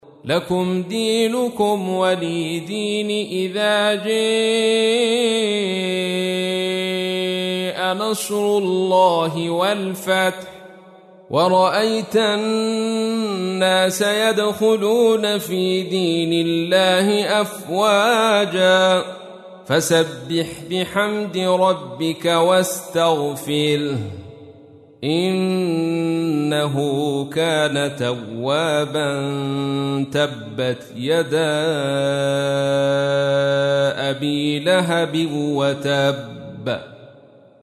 تحميل : 110. سورة النصر / القارئ عبد الرشيد صوفي / القرآن الكريم / موقع يا حسين